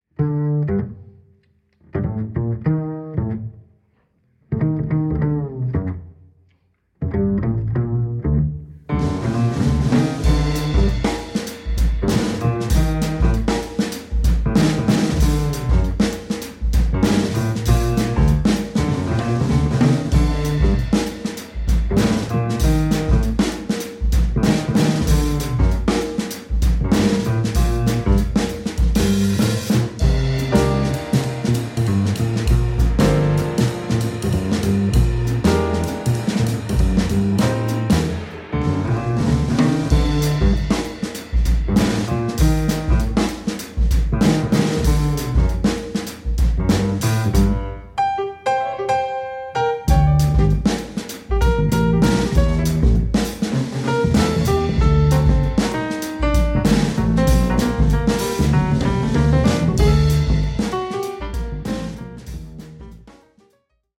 piano
batterie